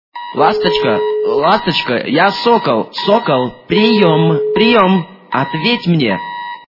» Звуки » Люди фразы » Ласточка моя! - Я Сокол, прием!
При прослушивании Ласточка моя! - Я Сокол, прием! качество понижено и присутствуют гудки.